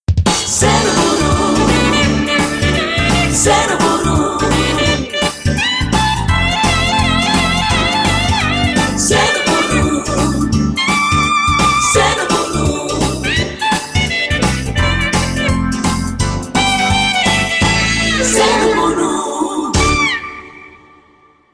Indicatiu llarg de l'emissora